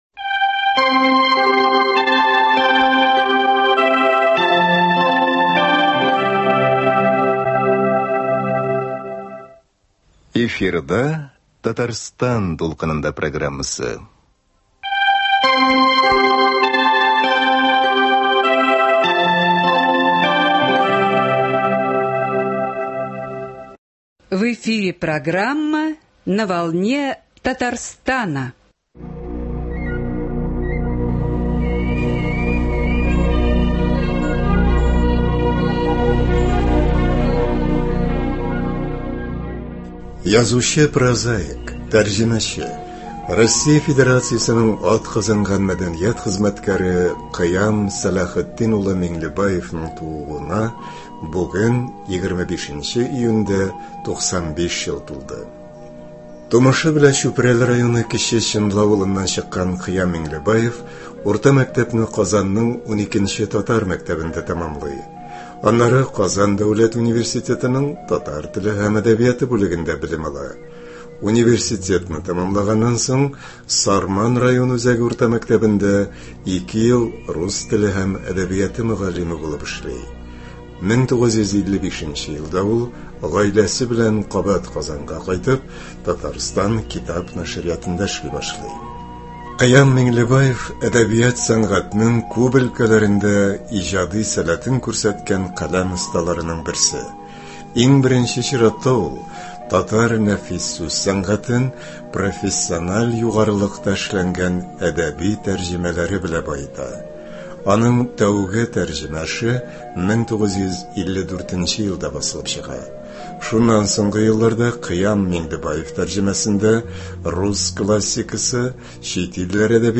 Тапшыру барышында аның радиобыз фондына 1987 елда язып алынган үз тавышын да ишетерсез.